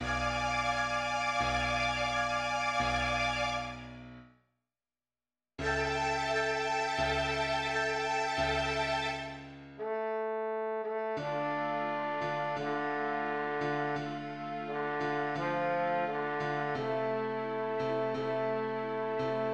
A dramatic diminished seventh chord announces the return of the slain Commendatore in the climactic finale of Mozart’s Don Giovanni.
diminished-chord.mp3